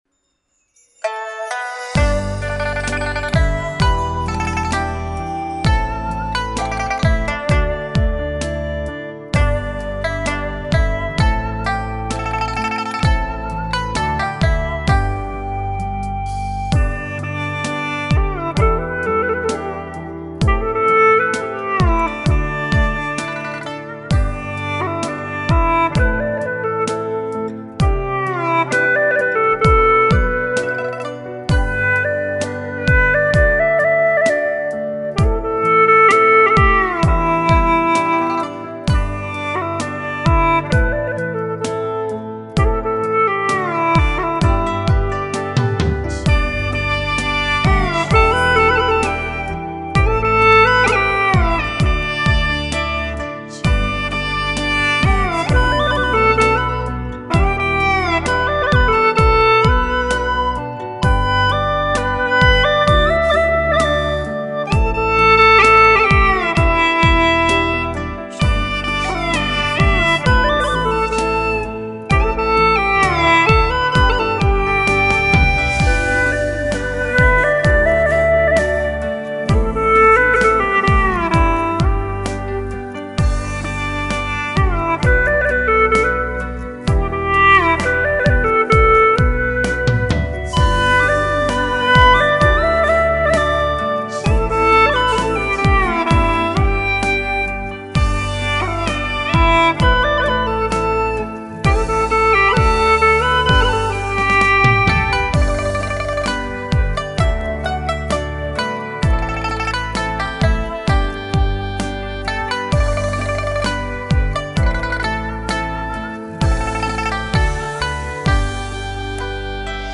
曲类 : 流行
【大小G调】